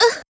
Ripine-Vox_Damage_01.wav